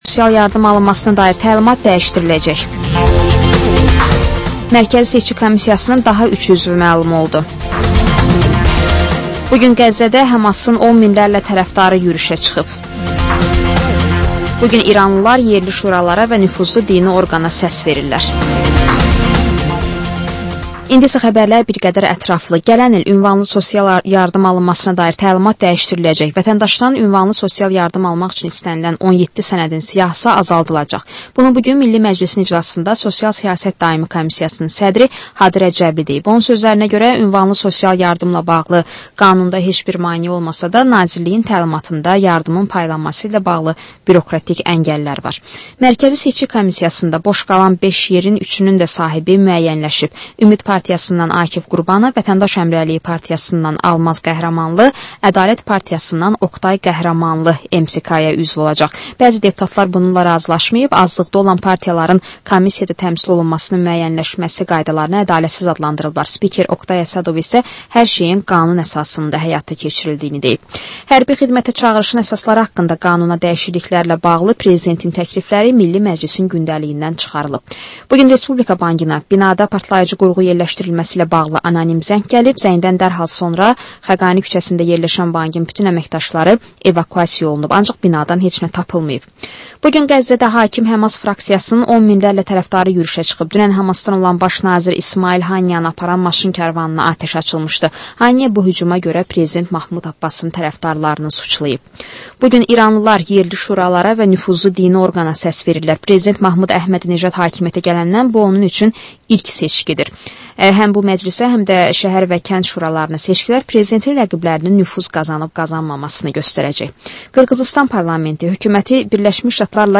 Həftənin aktual məsələsi barədə dəyirmi masa müzakirəsi